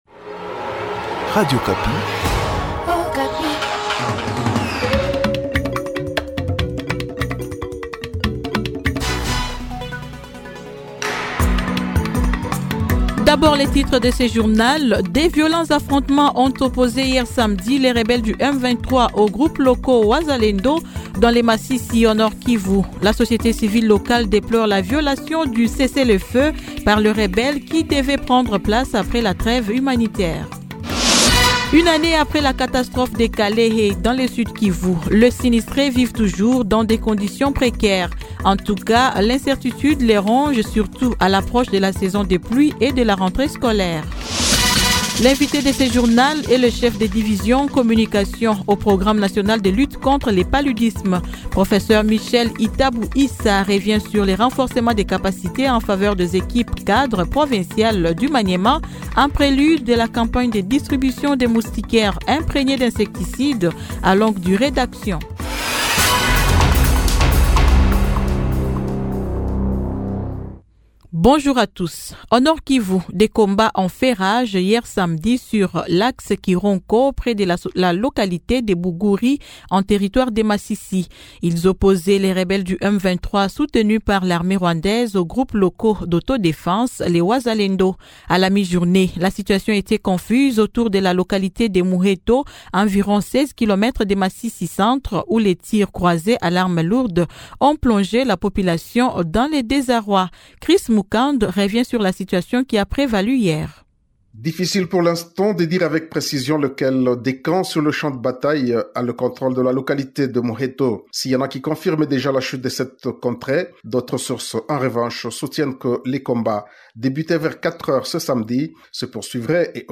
Journal matin 07H-08H